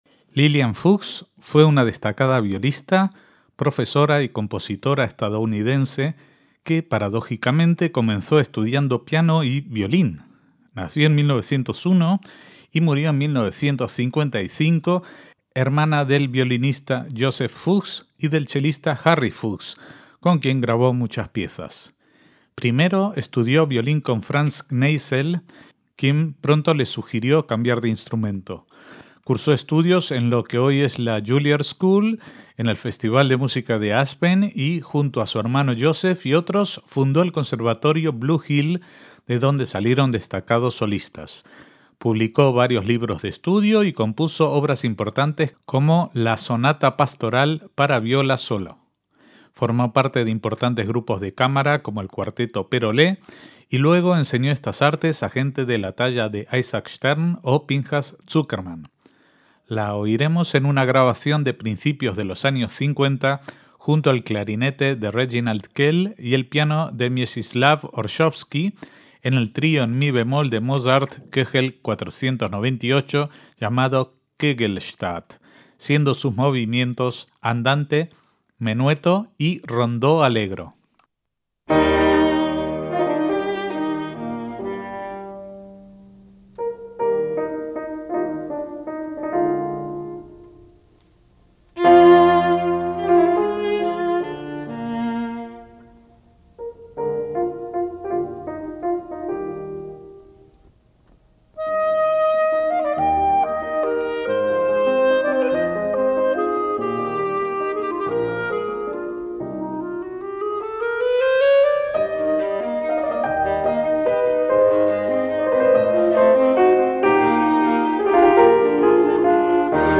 MÚSICA CLÁSICA - Lilian Fuchs fue una destacada violista nacida en Nueva York en 1901 en una familia musical, siendo hermana del violinista Joseph Fuchs (en la imagen, ambos) y el chelista Harry Fuchs.
La oiremos en el Trío en Mi bemol mayor para clarinete, viola y piano K.498 "Kegelst